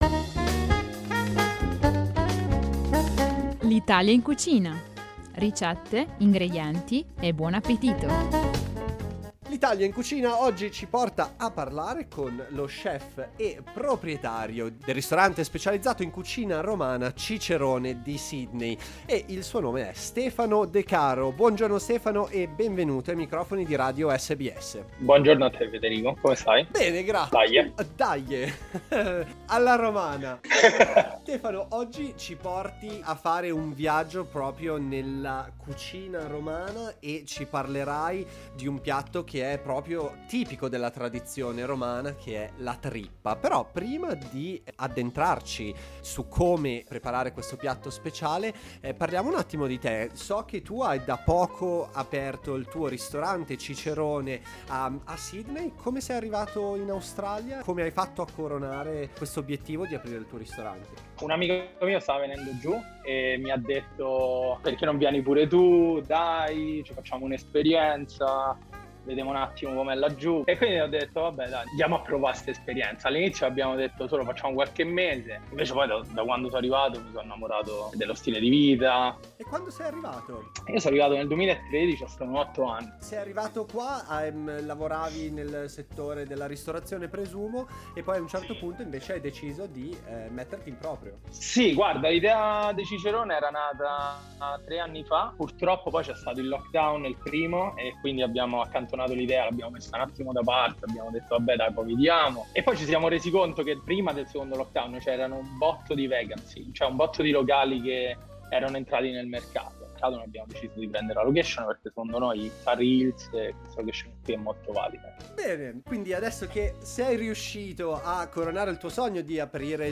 Potete scoprire come preparare questo piatto riascoltando la nostra intervista